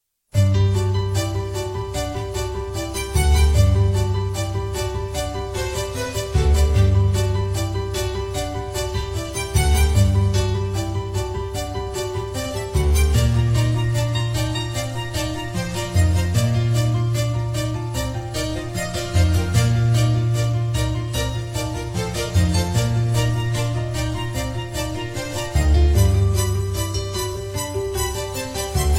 • Качество: 128, Stereo
без слов
сказочные